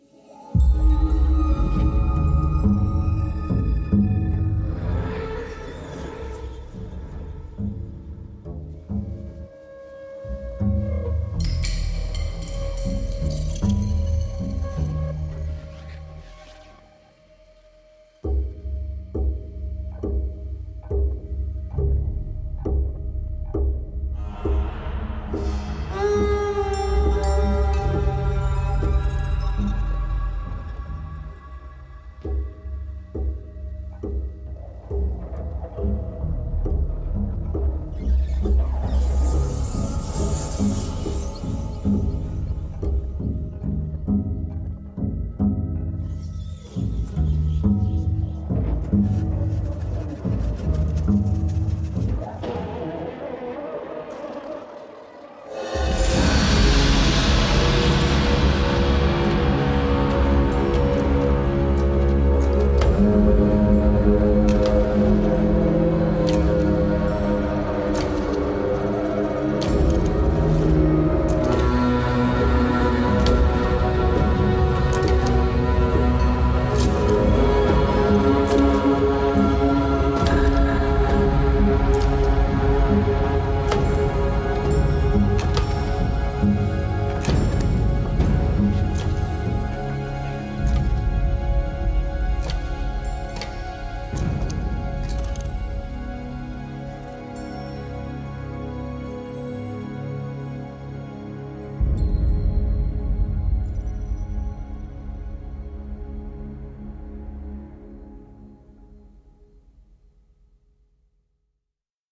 是一款独特的立式低音乐器，专为寻求新鲜原创声音的电影作曲家和音乐制作人而设计。
它还包括了多种旋律和纹理的演奏技法，例如鲸鱼连奏、滑音、双音、拇指勒弦、泛音、颤音、木身敲击、弓弦纹理等等。
是一款非常适合创作史诗般的配乐以及亲密而脆弱的音景的乐器，它可以为您的作品带来一些最具启发性的斯堪的纳维亚和欧洲氛围。